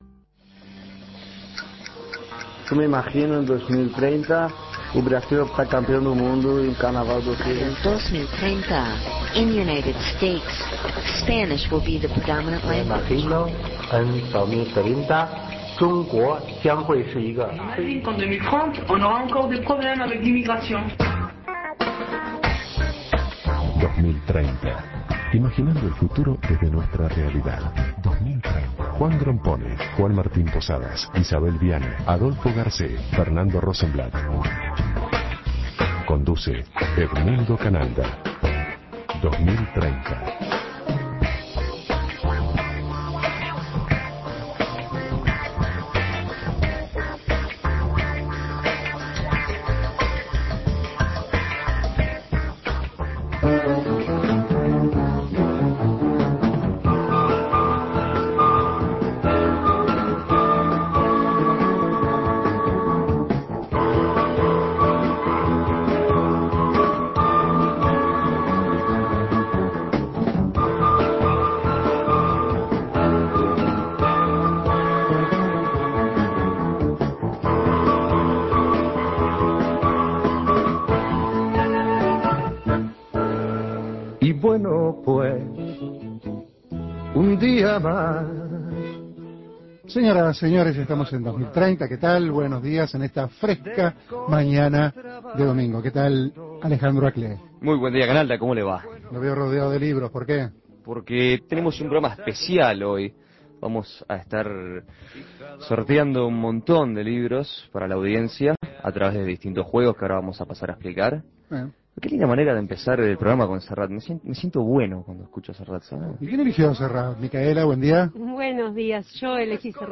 Programa especial.